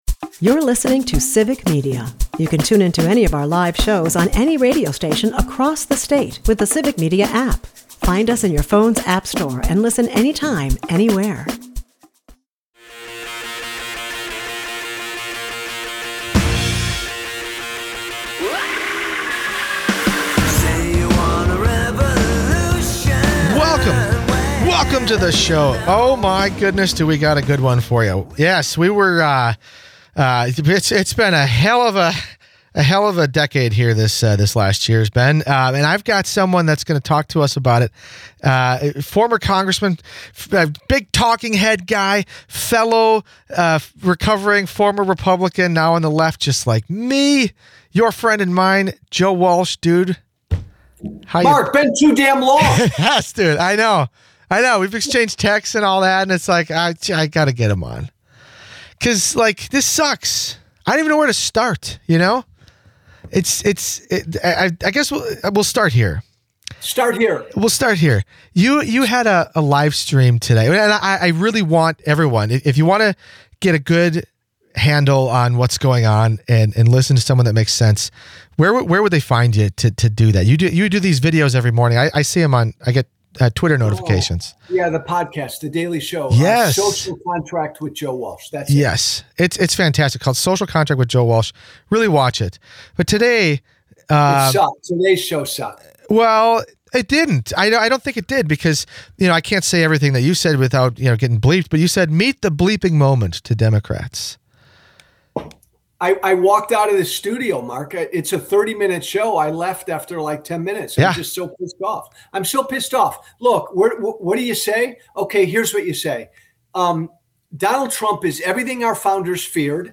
Former Republican Congressman and national pundit Joe Walsh stops by and talks about the current state of the country. Joe shares his thoughts on why he believes the Trump administration is going to suspend the midterm elections, and talks about how it's not as far-fetched as people may believe.